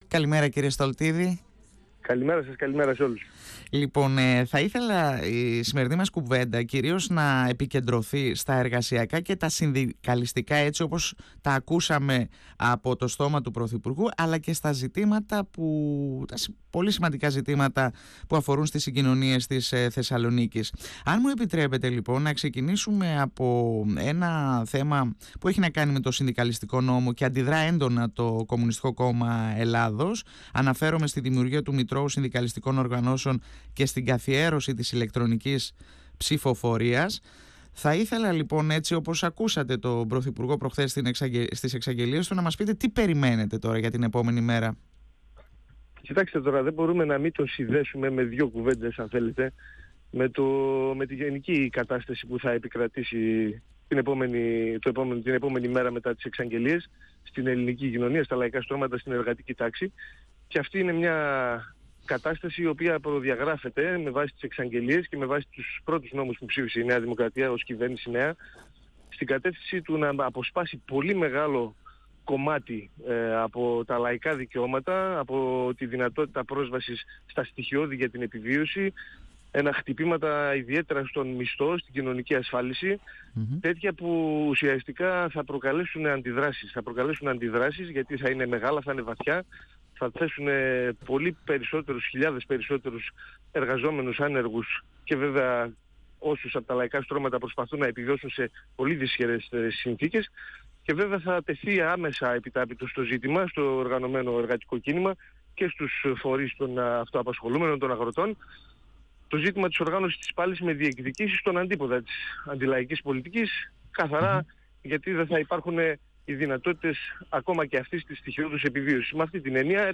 Στην εκτίμηση ότι η κυβέρνηση δεν έχει στόχο, ούτε στο ελάχιστο, να ελαφρύνει το λαό προέβη ο βουλευτής Β’ Θεσσαλονίκης του ΚΚΕ Λεωνίδας Στολτίδης μιλώντας στον 102 fm της ΕΡΤ3.